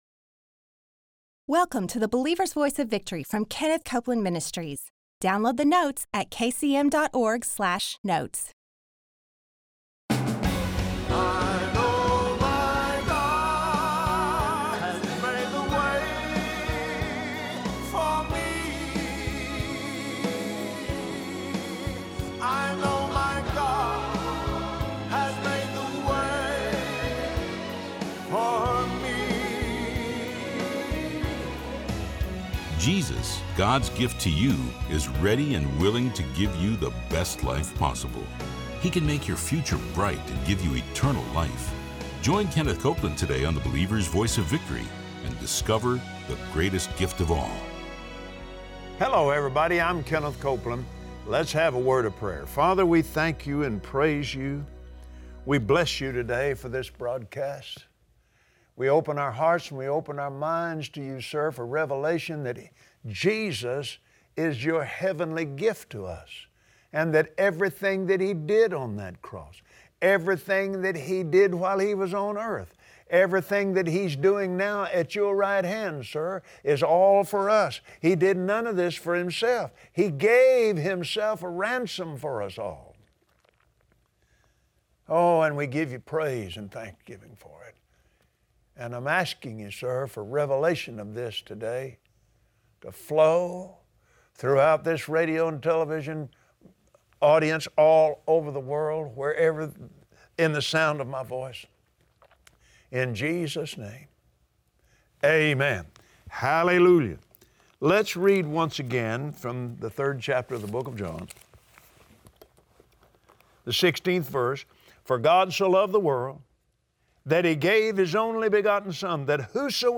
Believers Voice of Victory Audio Broadcast for Wednesday 02/01/2017 Watch Kenneth Copeland on the BVOV broadcast as he shares how healing belongs to believers and sinners through the Holy Spirit’s power.